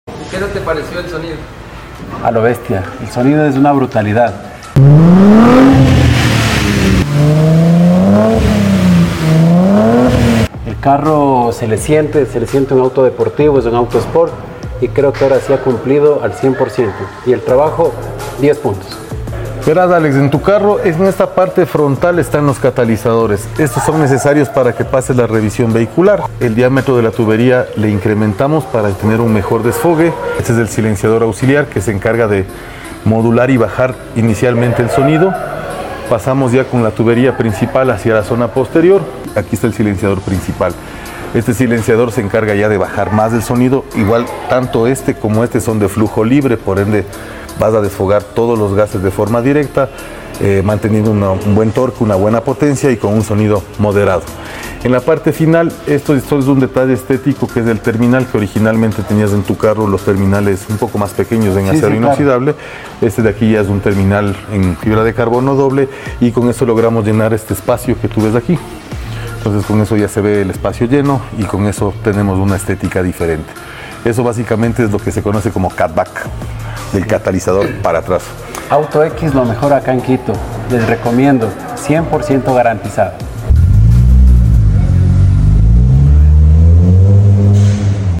¡Miren esta transformación que hicimos en este Mercedes Benz C230! 🚗💨 Instalamos un sistema de escape de alto rendimiento, añadimos elegantes terminales de fibra de carbono y ajustamos el sonido para que tenga una experiencia de manejo totalmente deportiva.